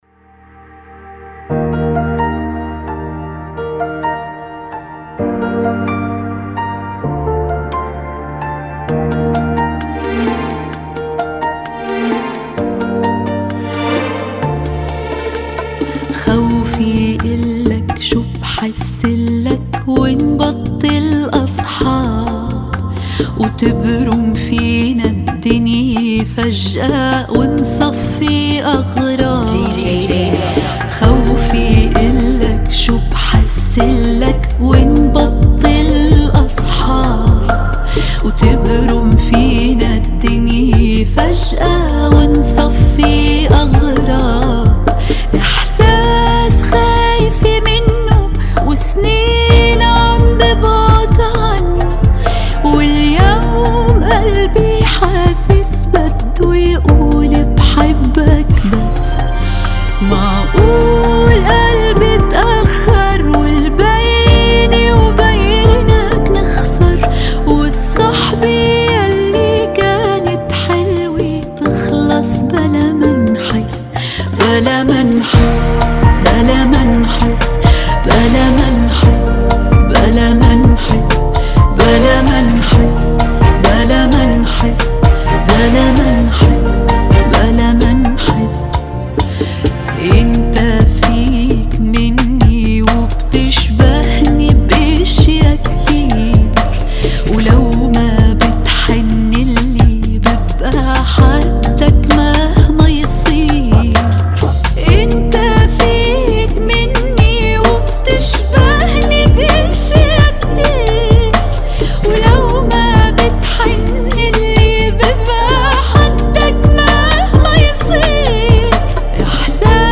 130 BPM
Genre: Bachata Remix